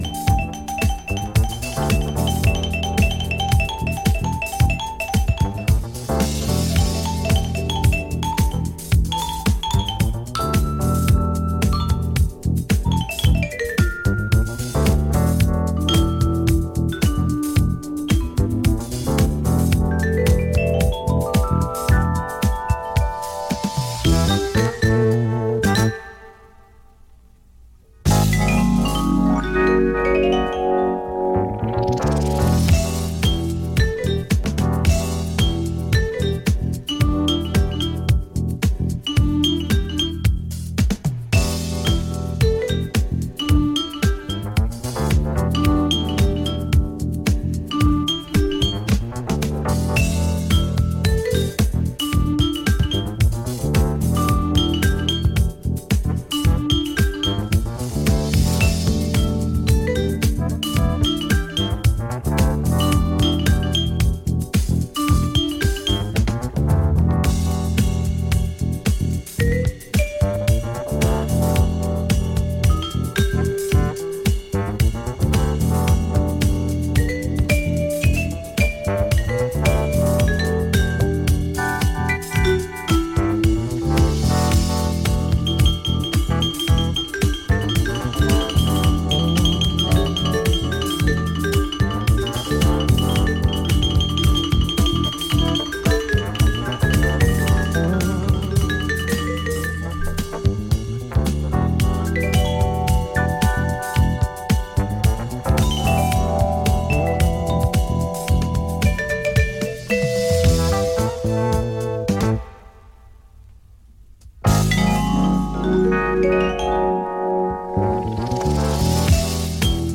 rooted in soul, jazz and groove.
signature flair for rhythmic depth and dancefloor flow